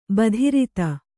♪ badhirite